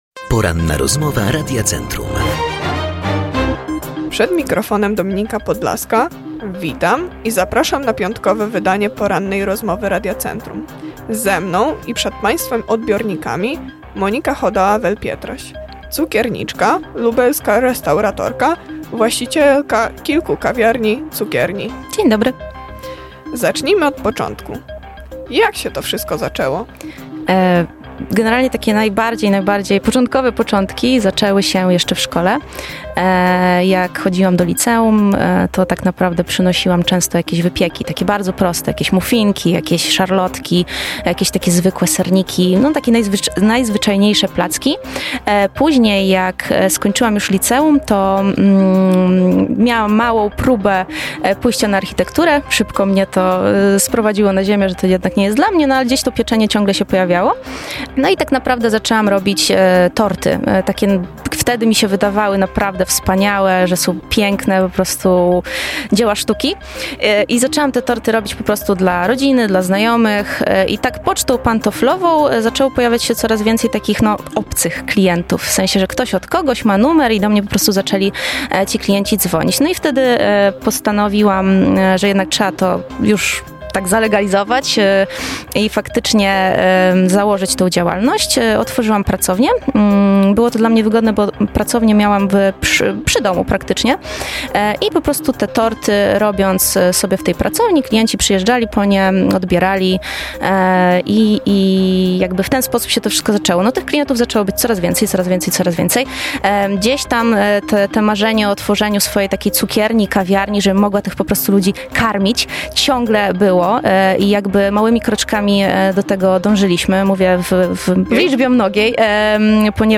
CIASTKA-SPOTIFY-ROZMOWA-1.mp3